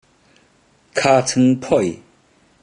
Click each Romanised Teochew word or phrase to listen to how the Teochew word or phrase is pronounced.
kha3chng3phoi4